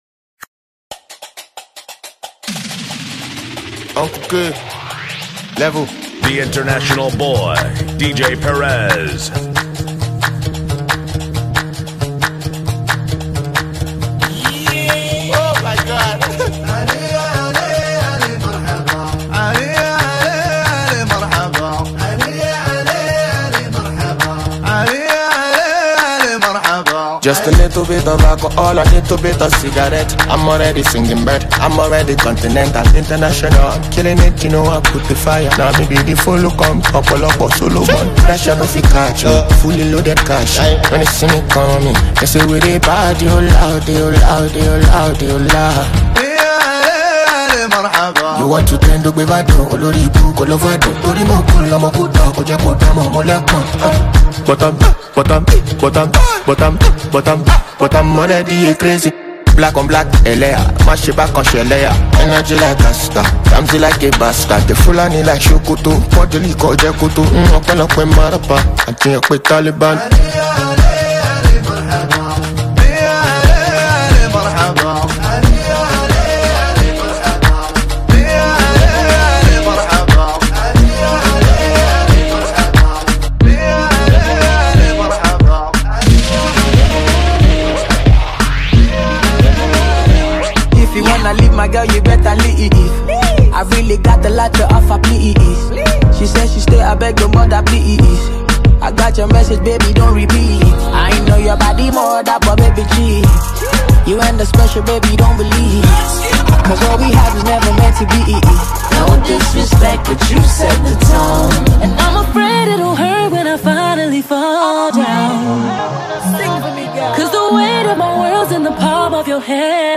high-energy, feel-good mix.
a carefully curated, non-stop playlist